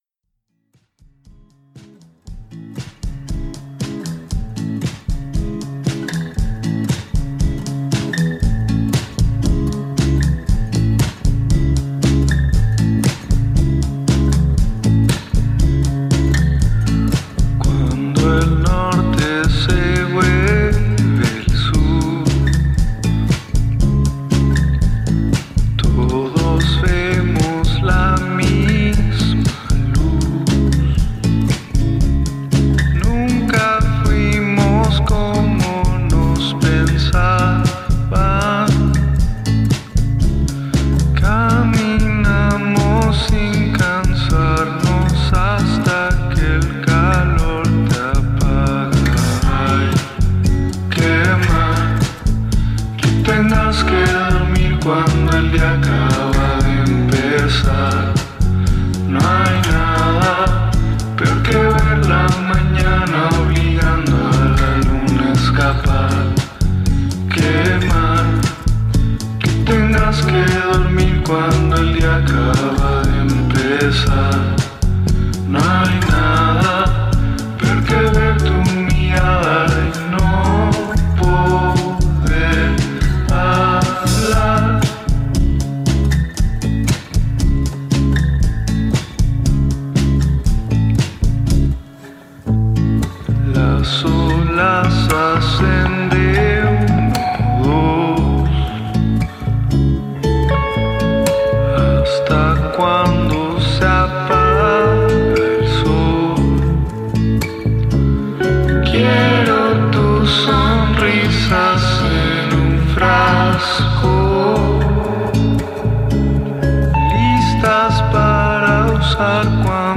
El Grupo de Apoyo Mutuo Zoroa nos trae en esta ocasión a La Bajona Colectiva quienes, entre otras cosas, nos cuentan la historia del colectivo y hacen una entrevista sobre el movimiento loco británico.